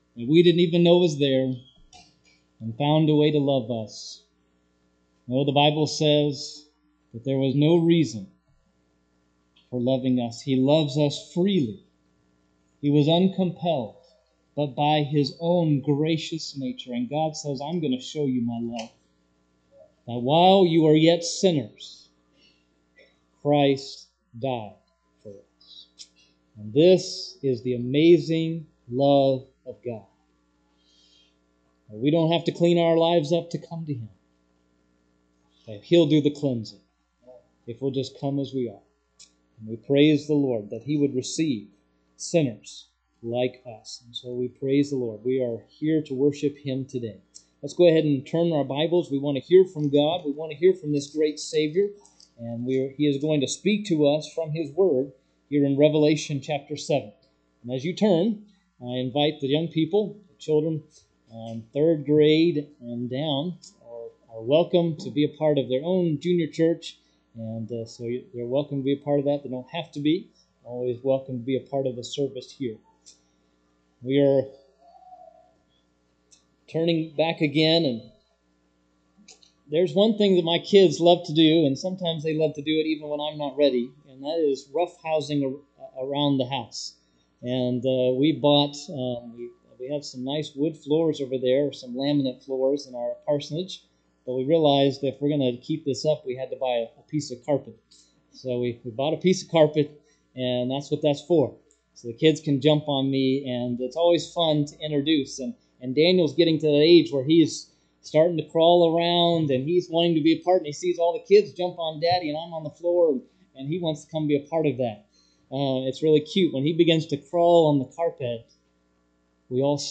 Sermons Sort By Date - Newest First Date - Oldest First Series Title Speaker Matthew.